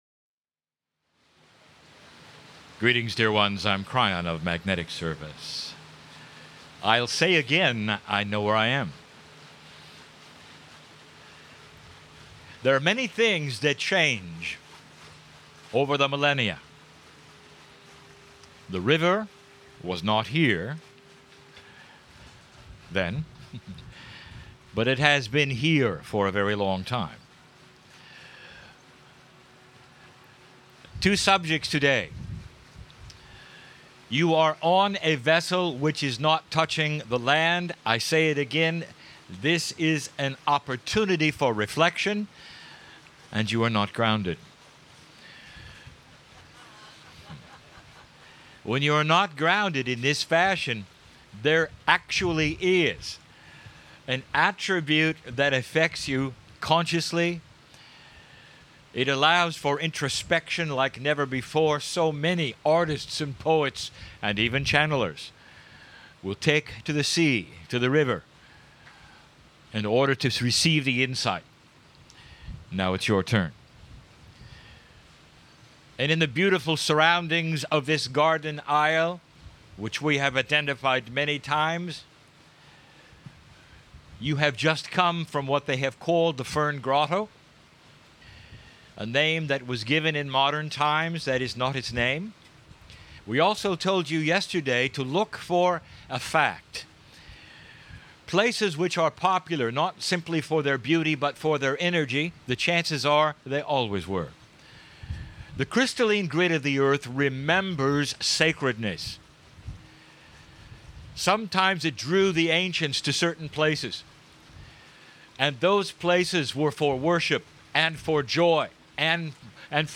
KRYON CHANNELLING AUGUST 12 - WAIMEA RIVER
Day-three-ontheboat.mp3